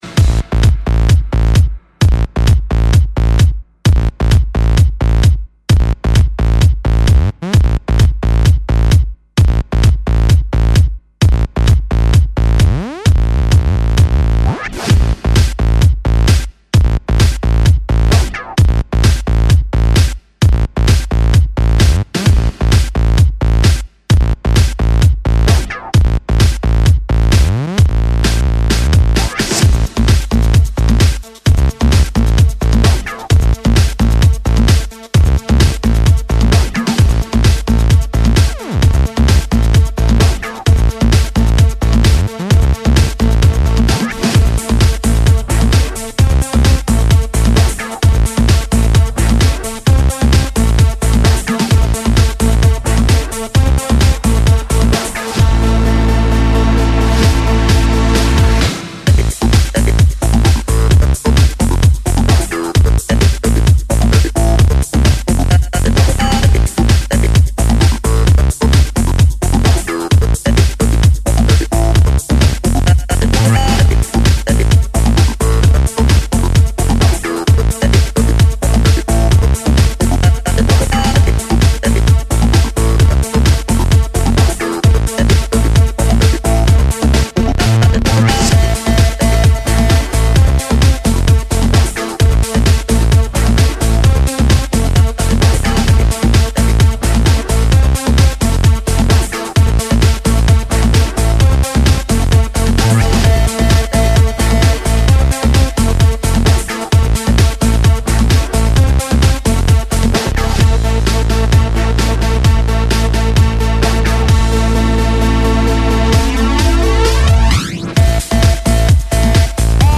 Украинская народная песня